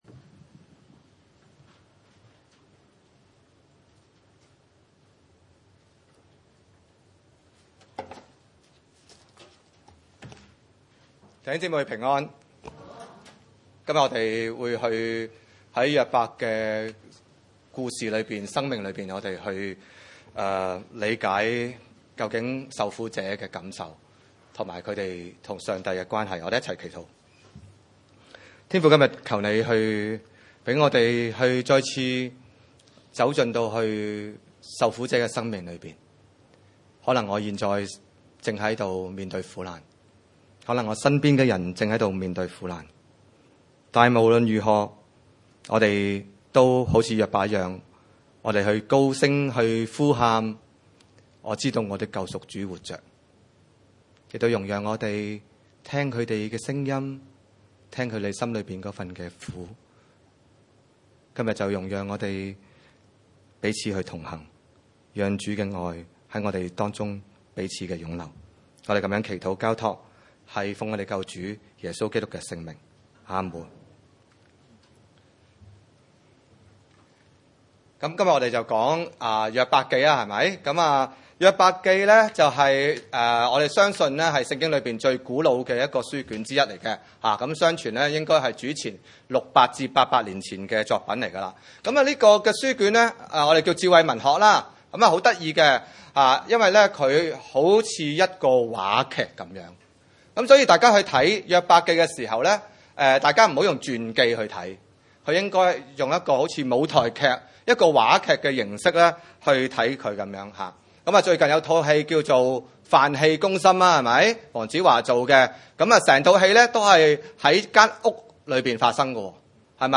經文: 約伯記 19:21-29 崇拜類別: 主日午堂崇拜 21 我的朋友啊，可憐我！